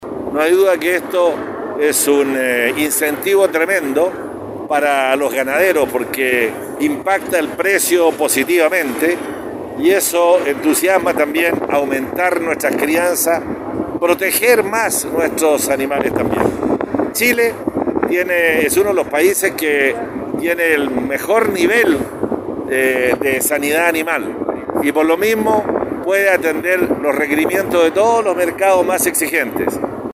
Despachan el mayor embarque de ganado vivo a China realizado desde nuestro país En la ocasión el Intendente de Los Lagos, Harry Jürgensen, señaló que este despacho es un incentivo para los productores locales.